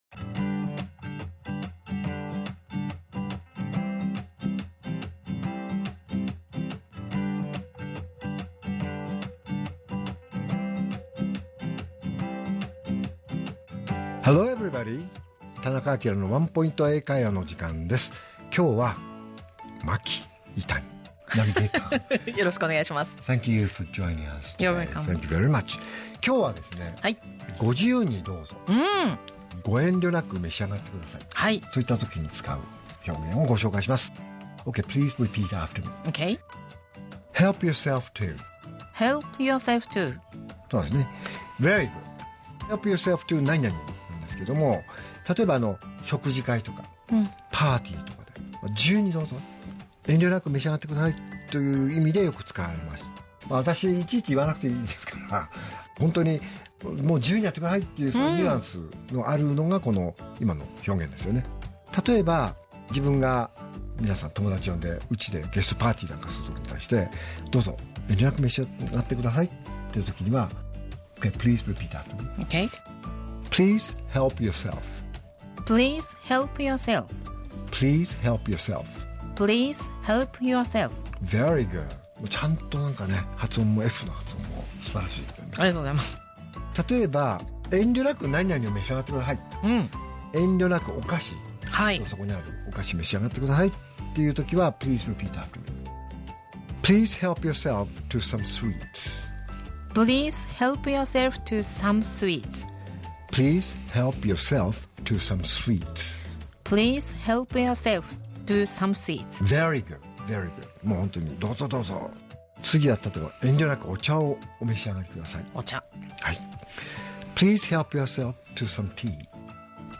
R7.5 AKILA市長のワンポイント英会話